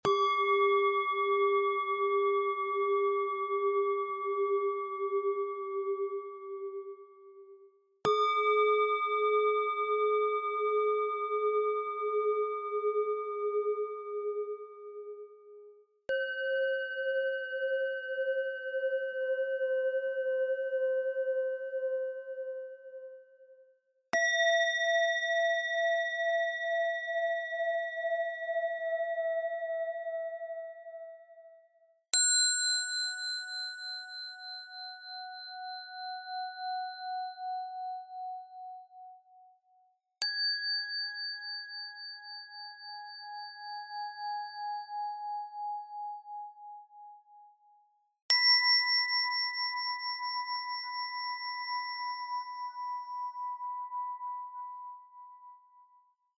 1 Minute All 7 Chakras Cleansing Tibetan Singing Bowls